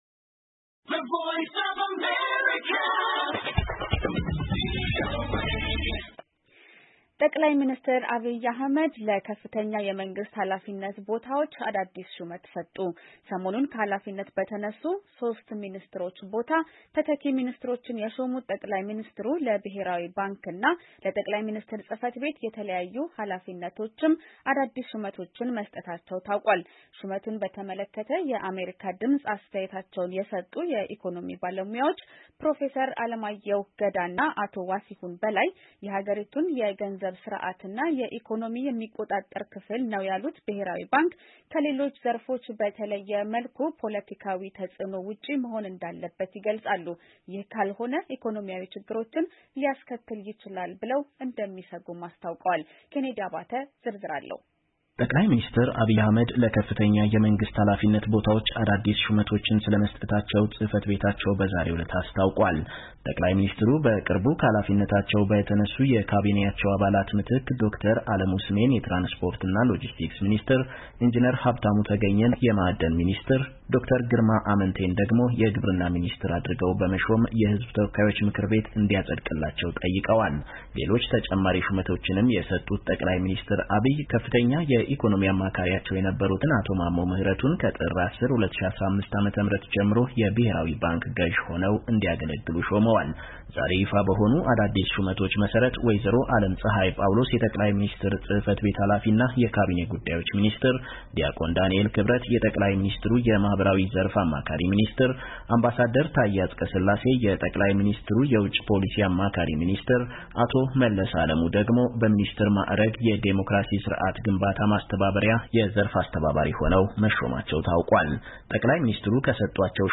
የባለሞያዎቹ አስተያየት የተካተተበትን ዘገባ ከተያያዘው ፋይል ይከታተሉ።